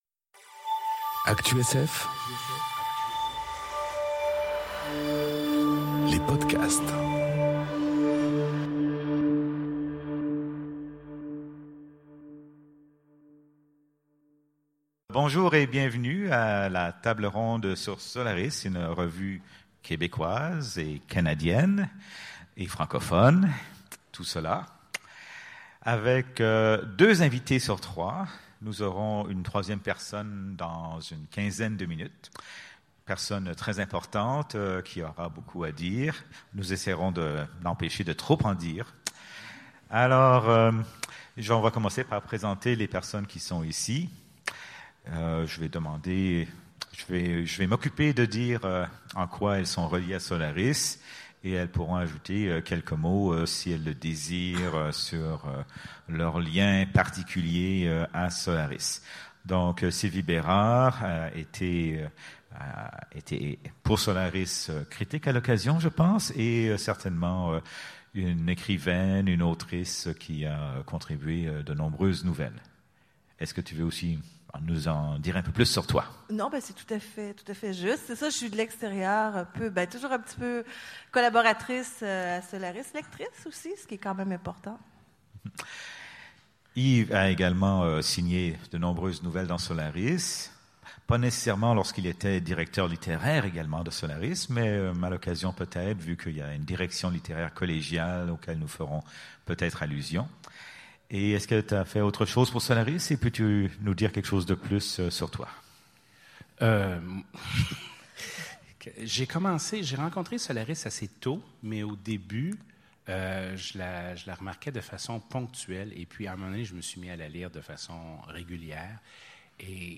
Conférence Solaris, une revue québécoise enregistrée aux Utopiales 2018